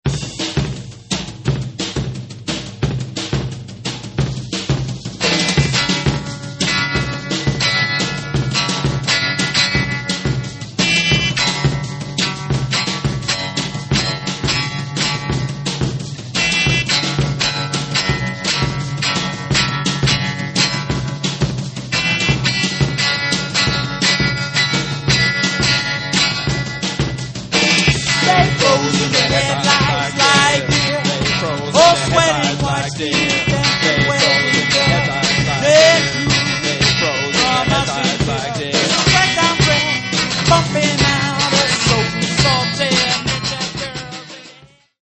1999 exciting medium voc.